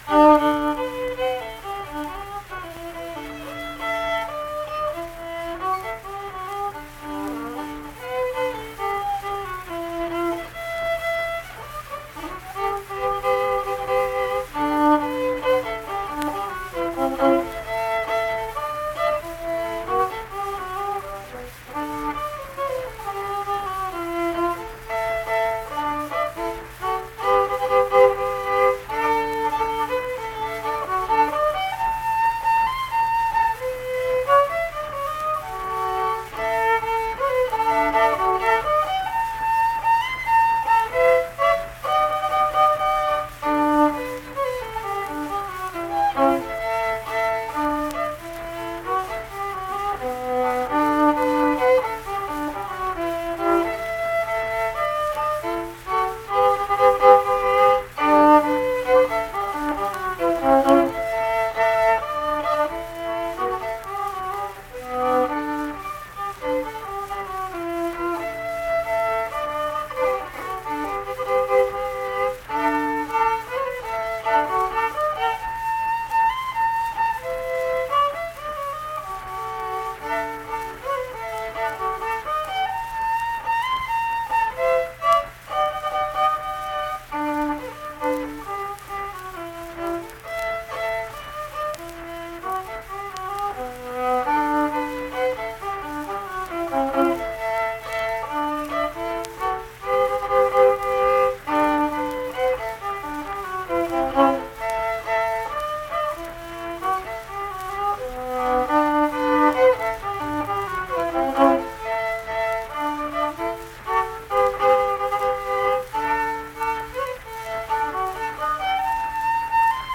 Unaccompanied fiddle music performance
Instrumental Music
Fiddle
Harrison County (W. Va.)